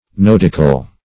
nodical - definition of nodical - synonyms, pronunciation, spelling from Free Dictionary
Nodical \Nod"ic*al\, a.